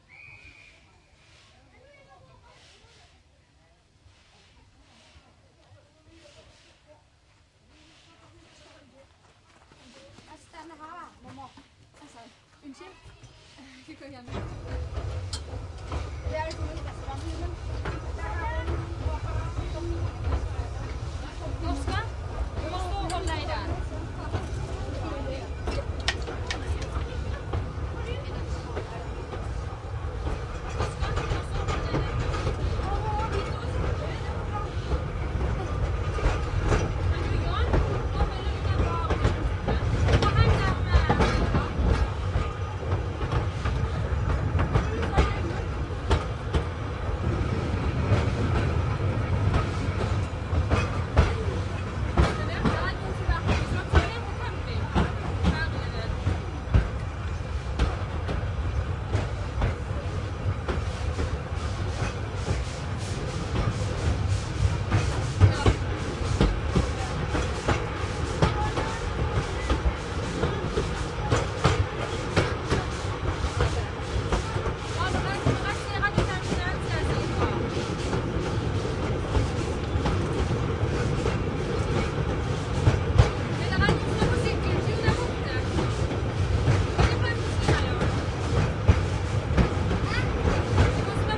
铁路蒸汽火车 " 蒸汽火车04
描述：在为游客乘坐的蒸汽火车上。
标签： 谈话 蒸汽 传球 小火车 机车 passenger-火车 铁路 蒸汽火车 历史 铁路 铁路路 铁路 聊天 汽机车
声道立体声